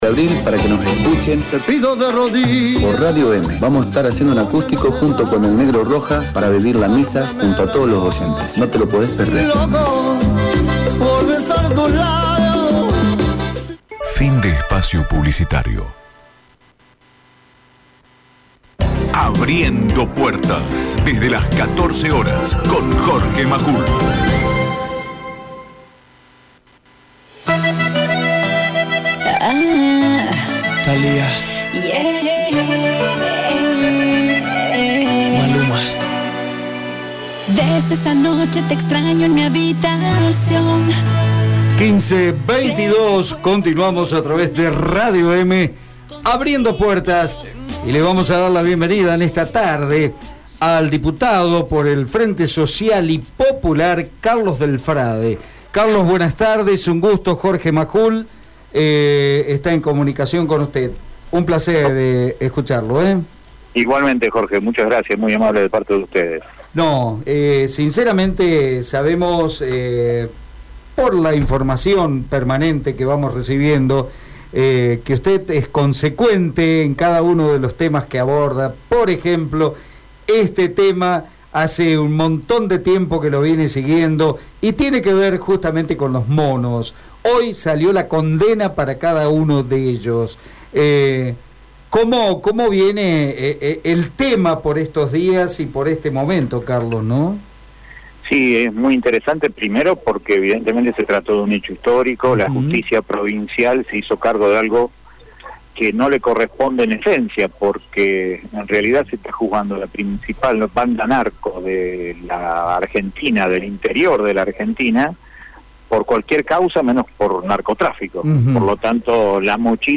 Integrantes de la banda rosarina de narcotraficantes fueron condenados por la Justicia de la Provincia en el día de hoy. El Diputado Carlos Del Frade brindó una entrevista al programa Abriendo Puertas de Radio EME sobre los detalles de la cusa y el gran problema que aqueja a Santa Fe: la droga.